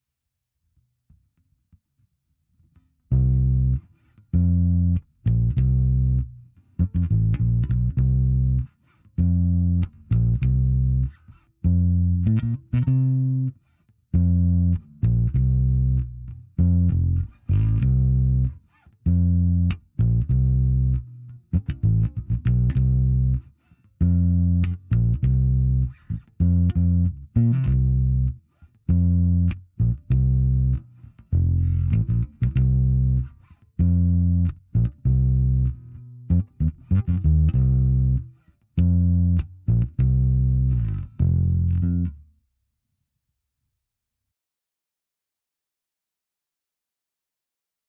The original link for the bass line expired, so I reposted it.
PlayAlong_bassline.mp3